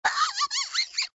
audio: Converted sound effects
AV_mouse_long.ogg